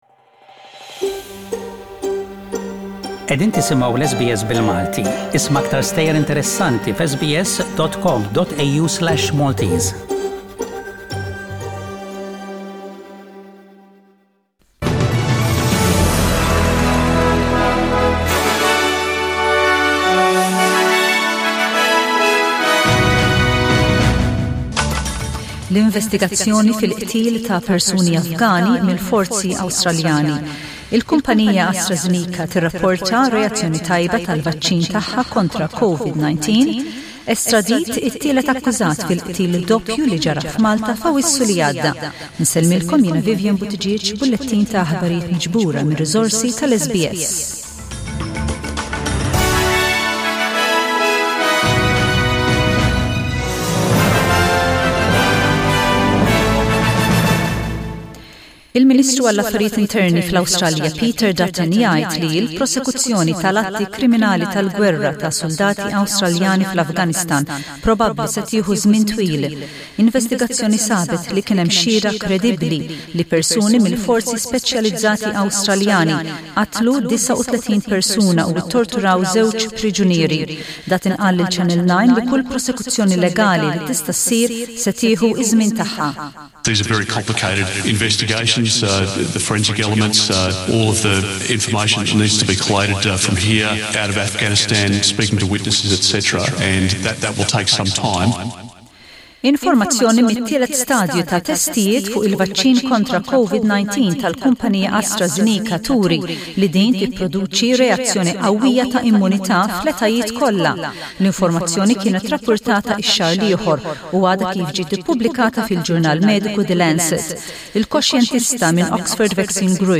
SBS Radio | News in Maltese: 20/11/20